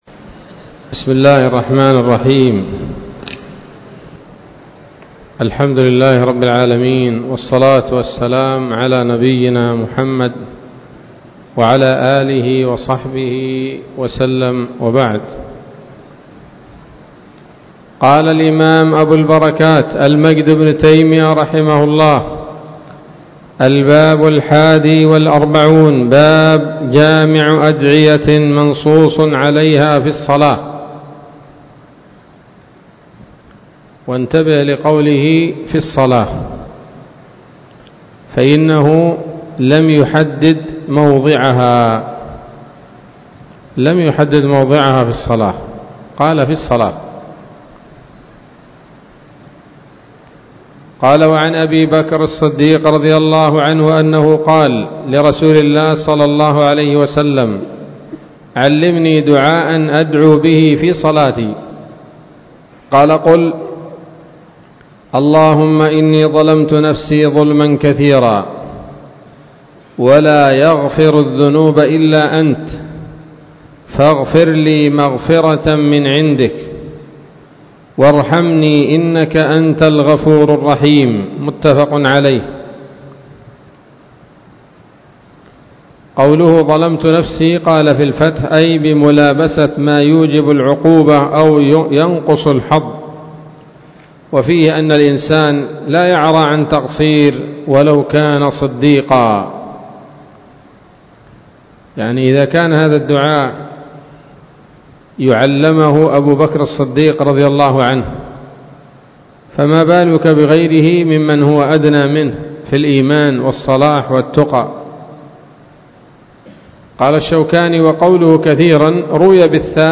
الدرس الخامس والثمانون من أبواب صفة الصلاة من نيل الأوطار